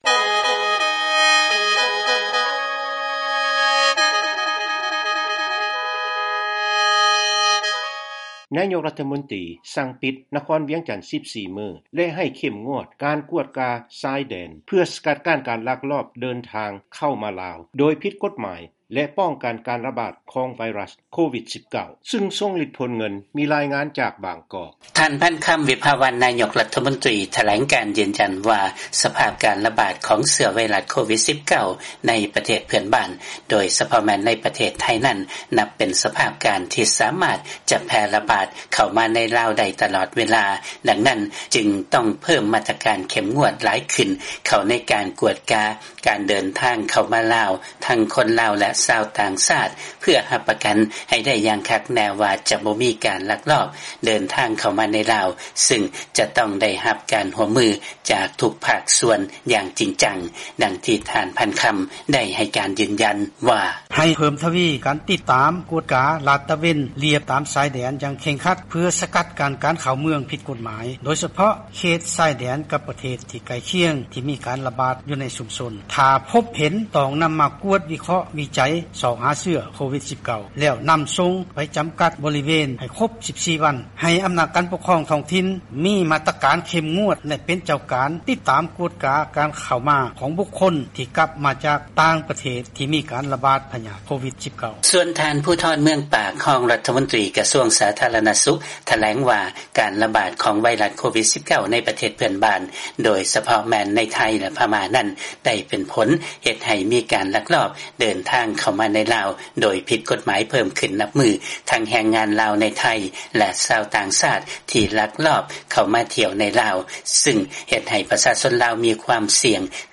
ມີລາຍ ງານຈາກບາງກອກ.